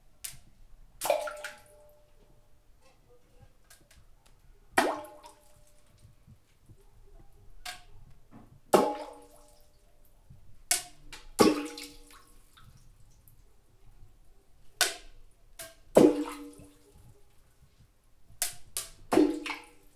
• little stones thrown through a fountain hitting the water.wav
Recording made with a Tascam DR 40 in a large diameter fountain, while throwing stones, hitting walls and falling into the water.
little_stones_thrown_through_a_fountain_hitting_the_water_ZgX.wav